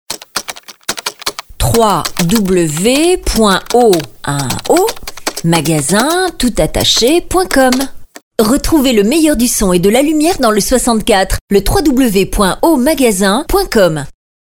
4000+ exemples de spots radio